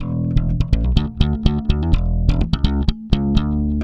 Index of /90_sSampleCDs/Best Service ProSamples vol.48 - Disco Fever [AKAI] 1CD/Partition D/BASS-SLAPPED